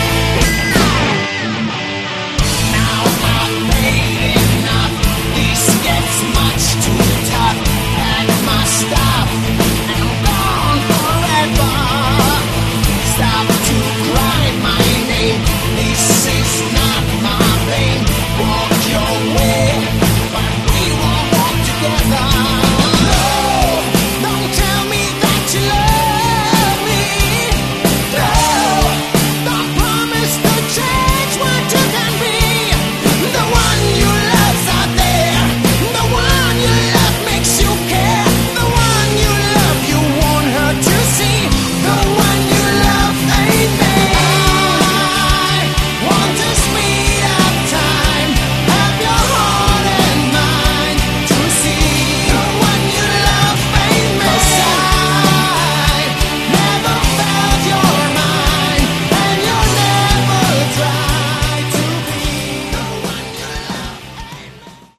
Category: Melodic Rock/AOR
Vocals
Guitars
Bass
Keyboards
Drums